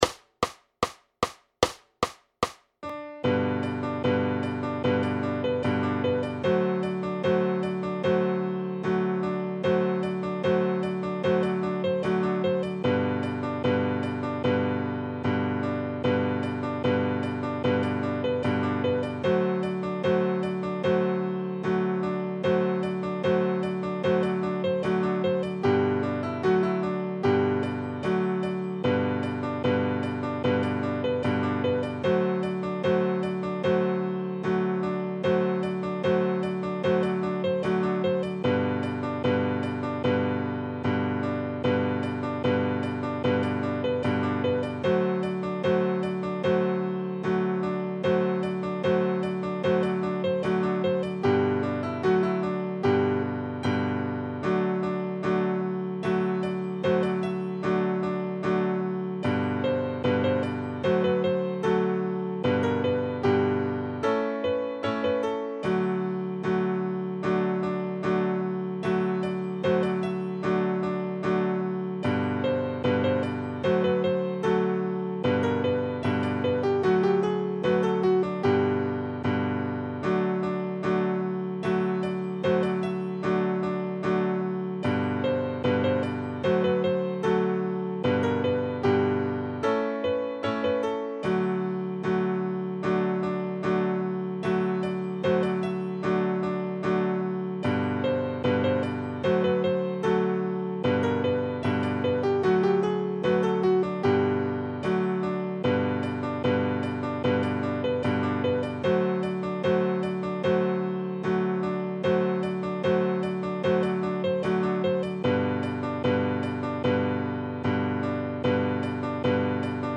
Noty na snadný klavír.
Hudební žánr Ragtime